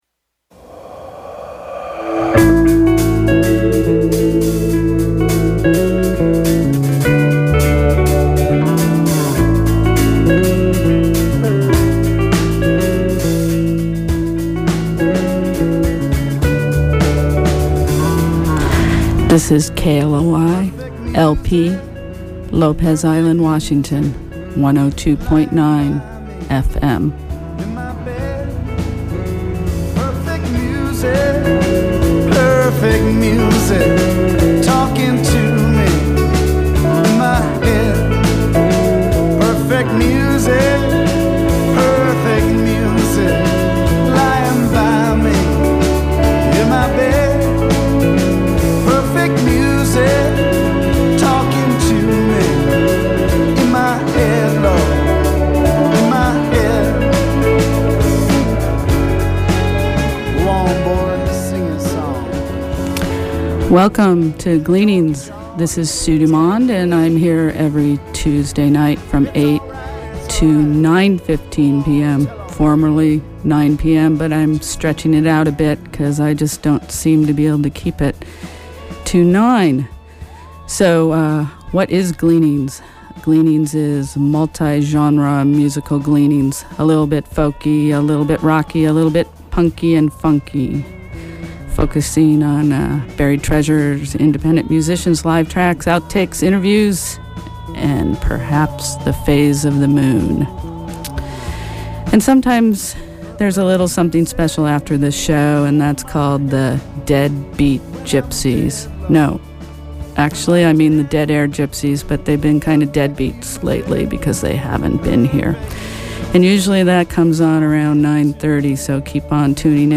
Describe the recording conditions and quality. I’m still recording the audio at home ( a very flawed system) and the stream must of rebuffered. After first set of music I had to patch two audio clips together and about 1 minute of audio was lost. ohhh well!